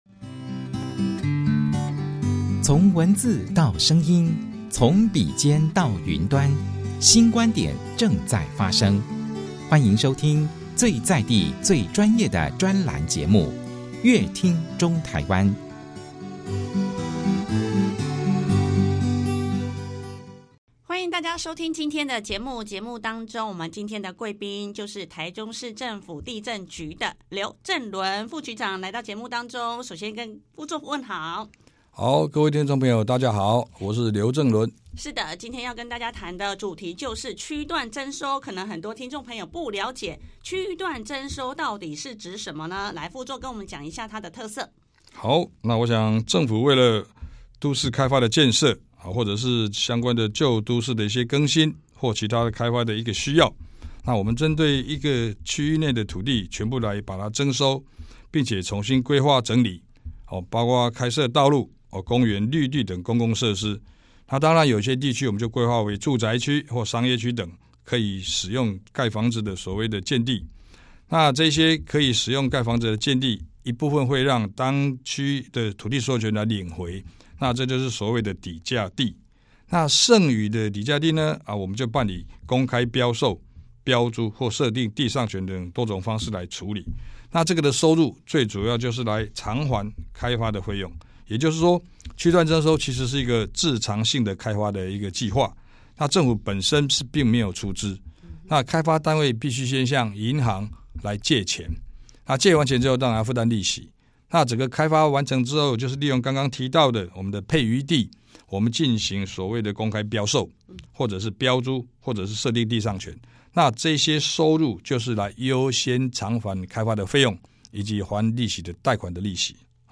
最後，近期臺中市標售土地有許多批評的聲音，地政局是如何回應?臺中市處分土地除了標售，還有甚麼土地利用其他方式呢?相關的提問，在專訪中劉副局長皆一一為大家解說，想知道更多精彩的內容，請鎖定本集節目。